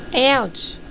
1 channel
00073_Sound_OUCH!.mp3